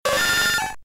Cri de Nidorino K.O. dans Pokémon Diamant et Perle.